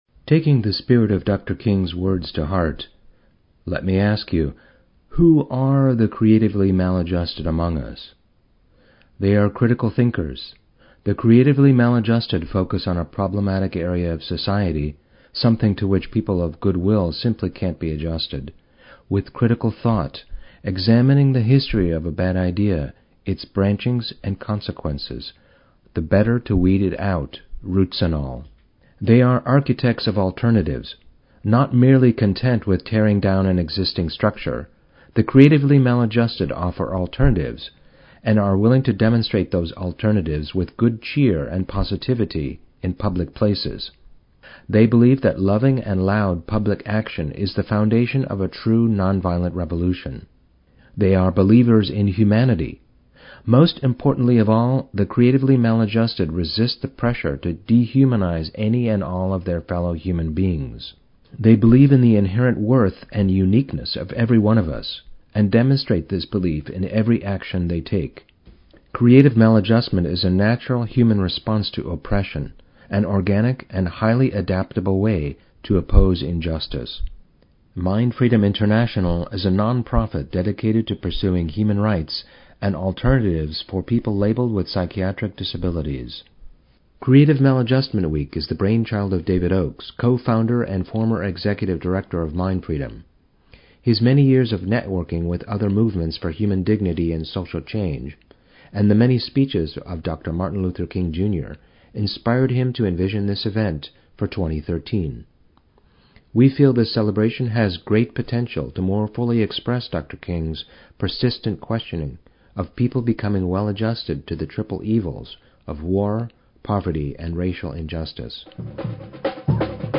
Voiceover narration for MindFreedom International slide show (.mp3)
CMWeek2014-NarrationOnly-2min.mp3